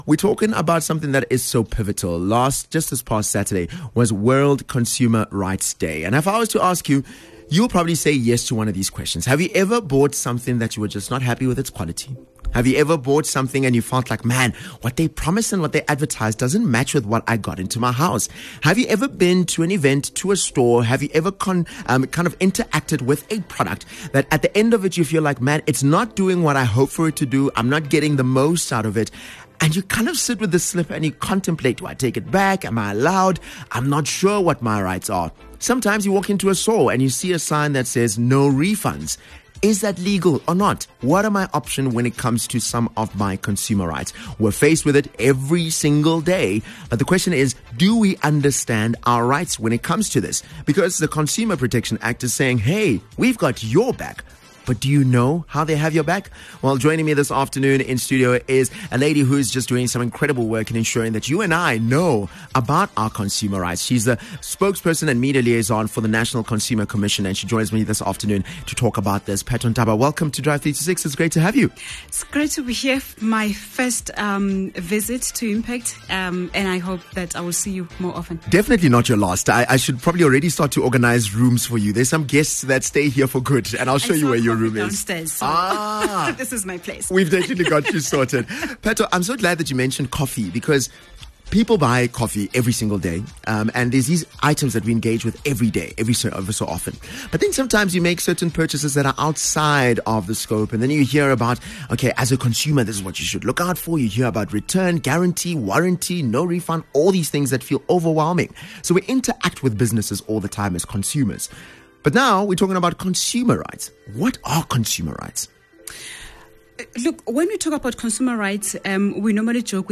In this insightful discussion, they unpack consumer rights, how to protect yourself from unfair business practices, and what to do if you’ve been wronged as a consumer. Don’t miss this important conversation that empowers you to make informed decisions in the marketplace!